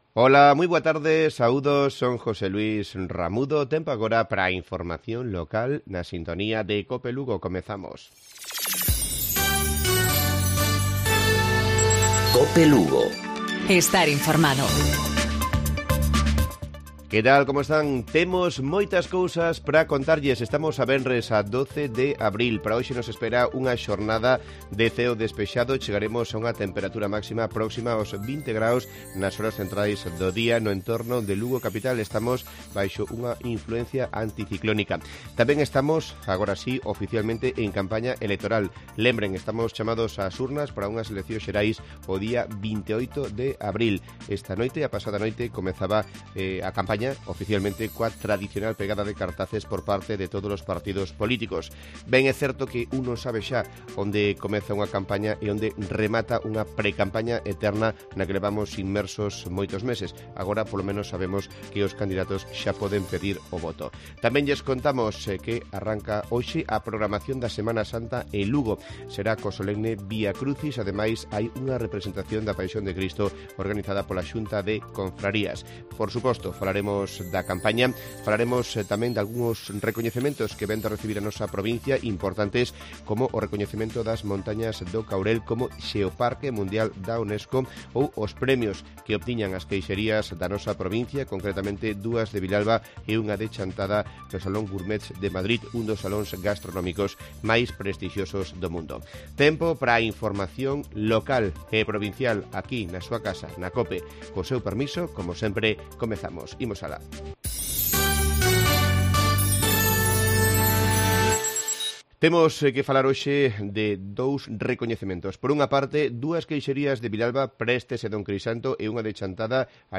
Informativo Provincial viernes 12 de abril 12:50-13:00 horas